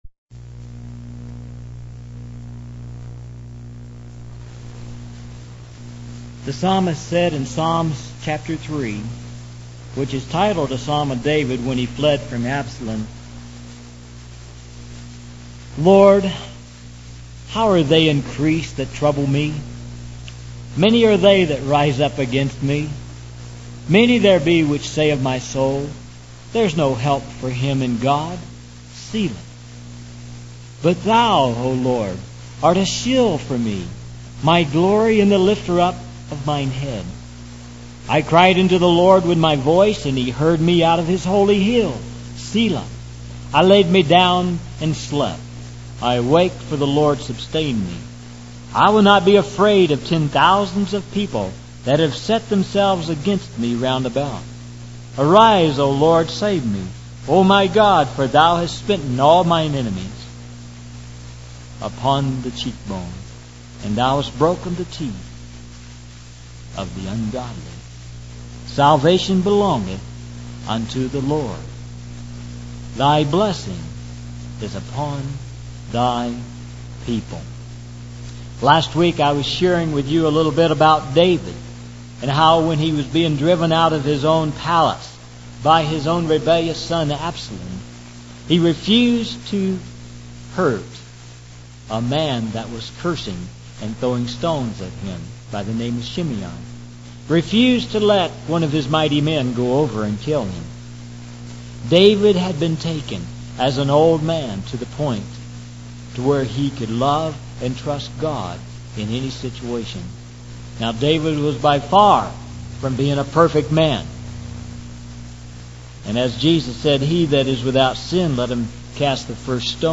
In this sermon, the speaker emphasizes the importance of having the right actions and words, accompanied by the right attitudes. He explains that God allows trials in our lives to reveal the deceitfulness of our own hearts.